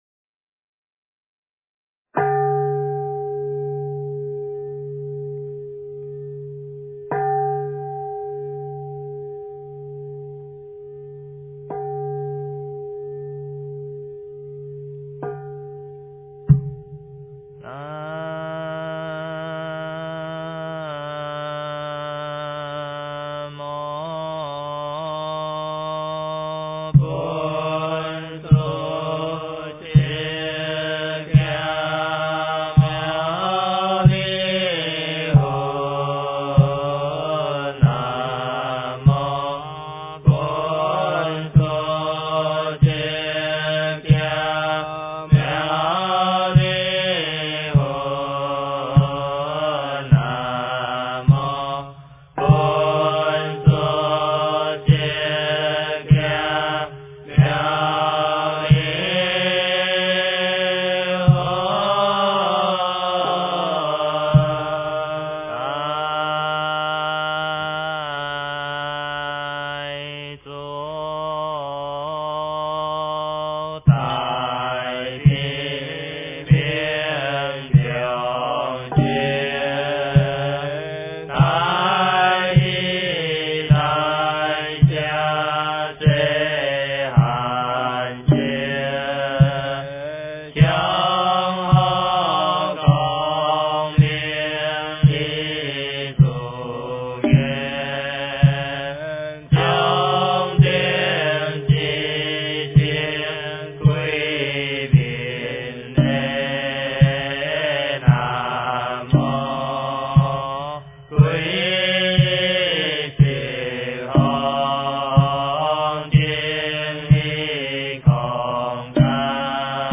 八十八佛大忏悔文-闽南语--圆光佛学院众法师
八十八佛大忏悔文-闽南语--圆光佛学院众法师 经忏 八十八佛大忏悔文-闽南语--圆光佛学院众法师 点我： 标签: 佛音 经忏 佛教音乐 返回列表 上一篇： 普门品+大悲咒--僧团 下一篇： 赞佛偈--佛光山中国佛教研究院 相关文章 《妙法莲花经》法师功德品 第十九--佚名 《妙法莲花经》法师功德品 第十九--佚名...